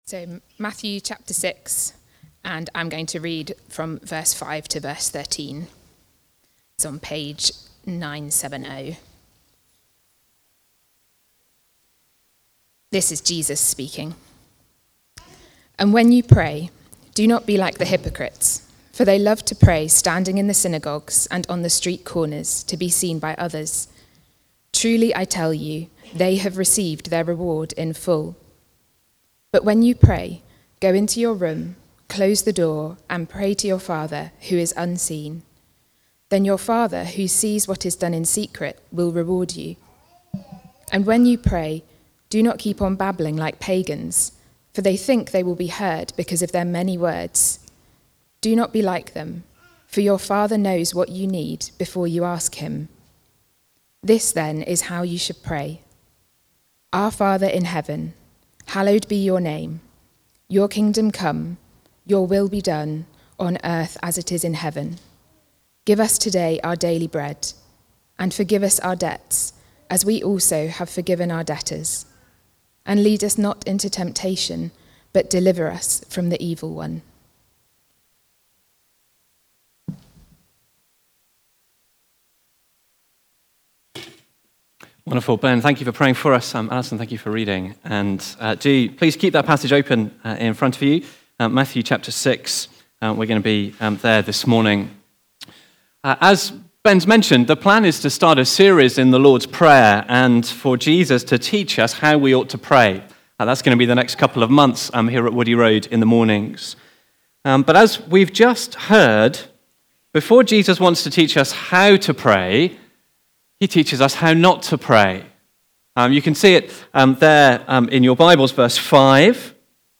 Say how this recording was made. When You Pray... (Matthew 6:5-8) from the series The Lord's Prayer. Recorded at Woodstock Road Baptist Church on 01 June 2025.